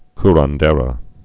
(krən-dârō)